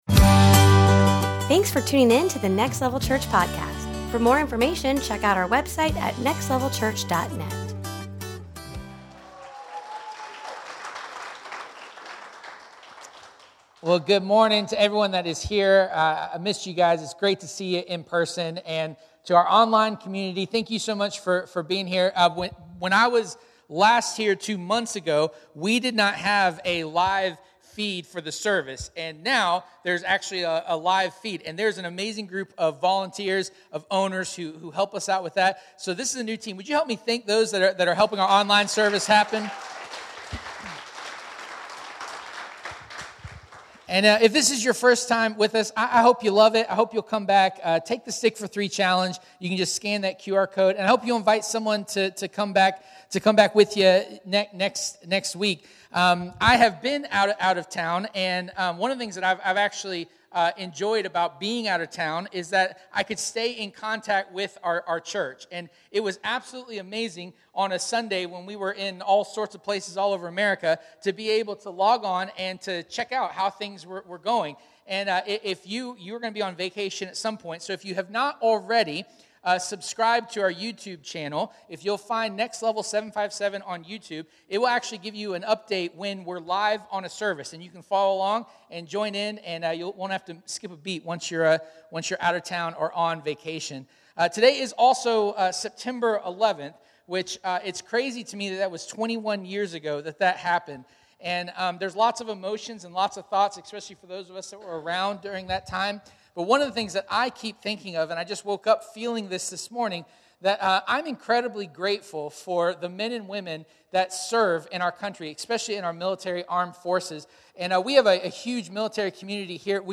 Your Religion is Broken Service Type: Sunday Morning « Compadres of Next Level